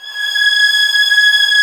Index of /90_sSampleCDs/Roland LCDP13 String Sections/STR_Violins III/STR_Vls6 mf%f St